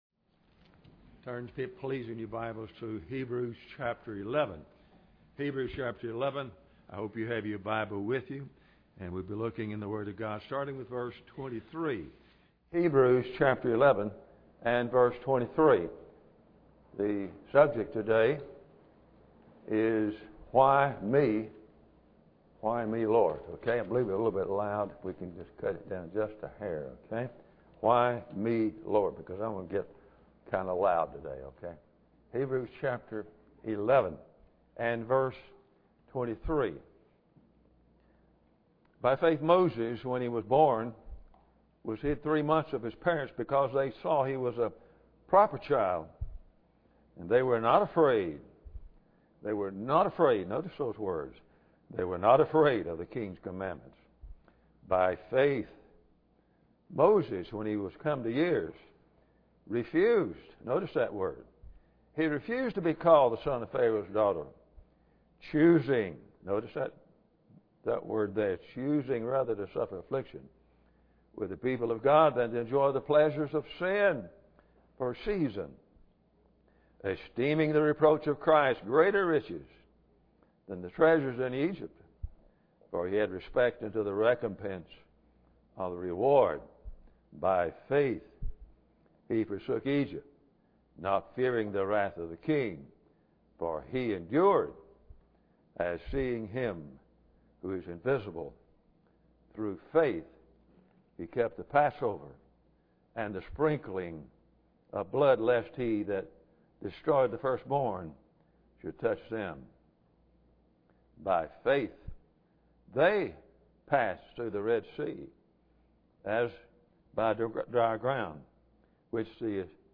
Hebrews 11:23-29 Service Type: Sunday Morning Bible Text